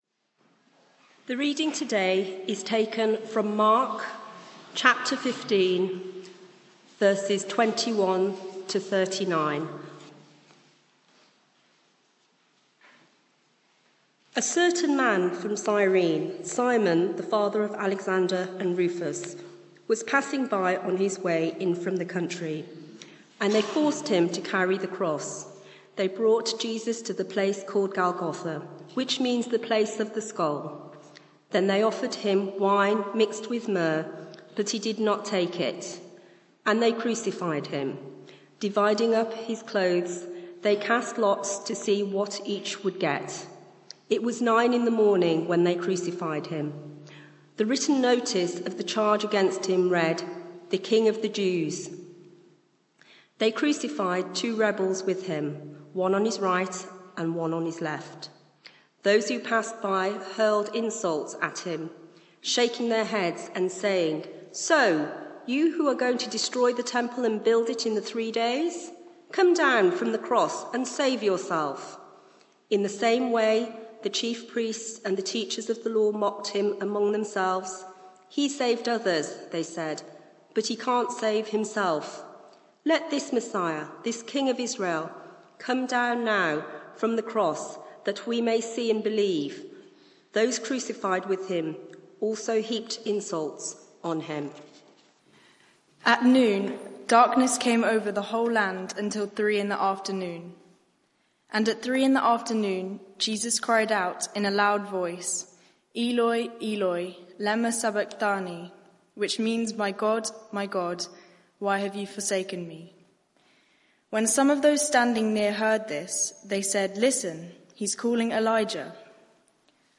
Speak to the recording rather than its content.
Media for 6:30pm Service on Sun 13th Apr 2025 18:30 Speaker